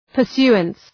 {pər’su:əns}